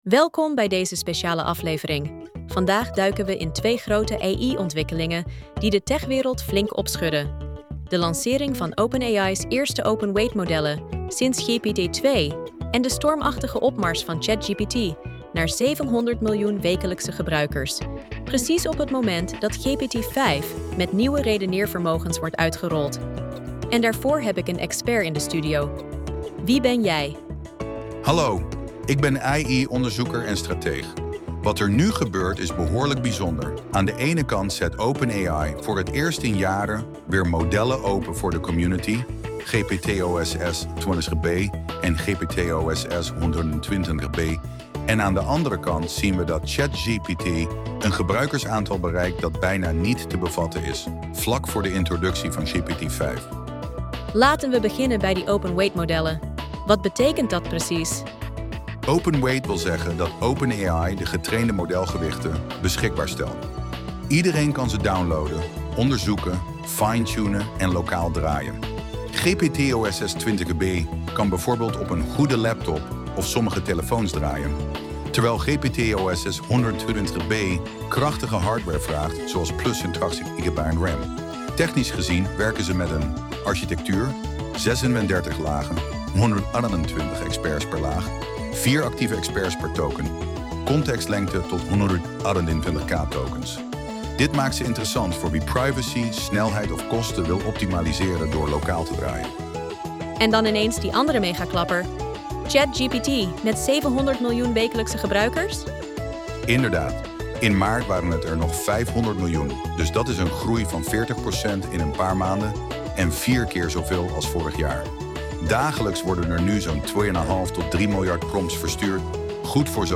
Podcast gegenereerd van geüploade script: Dubbel AI-nieuws, dubbel zoveel impact